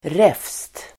Ladda ner uttalet
Uttal: [ref:st]